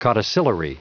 Prononciation du mot codicillary en anglais (fichier audio)
Prononciation du mot : codicillary